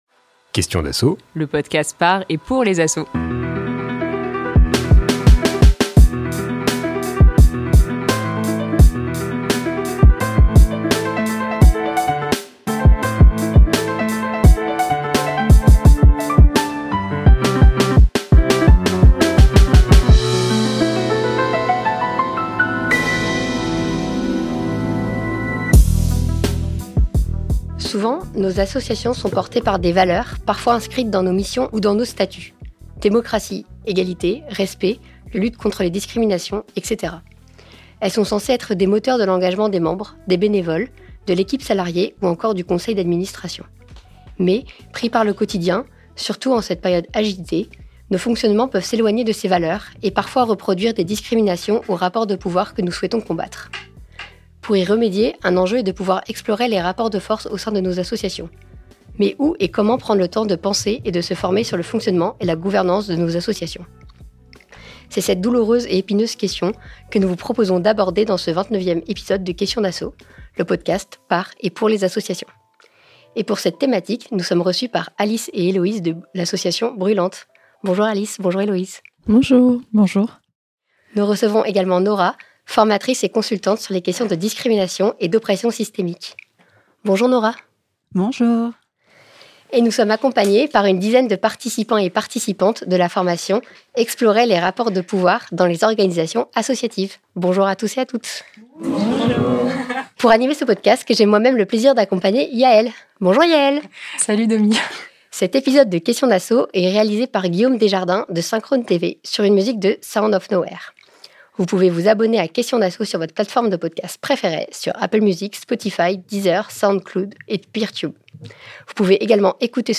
Pour cet épisode inédit, Questions d'Asso s'est immergé dans une formation de quatre jours sur les rapports de pouvoir dans les organisations associatives.
Douze participant·es partagent leurs vécus et cherchent des leviers concrets pour transformer la gouvernance de leurs structures.